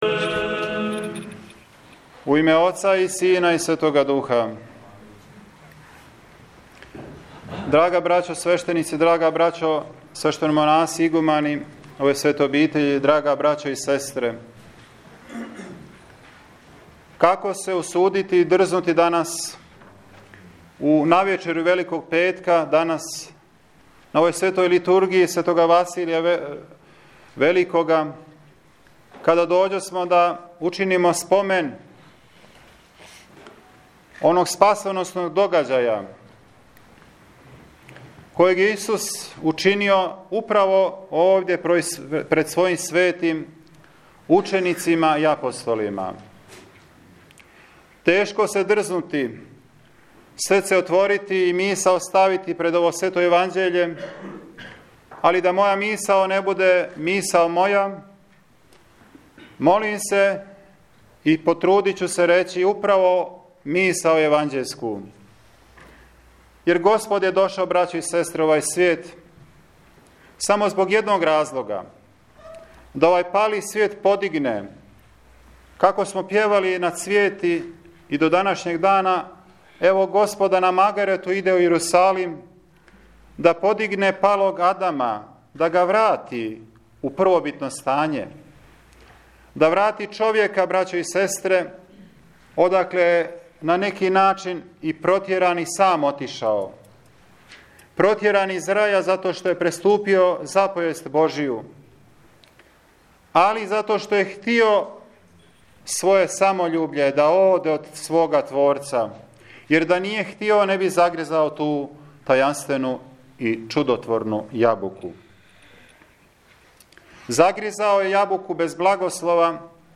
На Велики Четвртак у Саборном манастирском храму Свете великомученице Марине служена је Света Литургија Светог Василија Великог.
Бесједа Епископа Сергија на Велики четвртак 2018
Бесједа-Епископа-Сергија-на-Велики-четвртак-2018.mp3